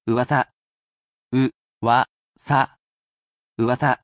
uwasa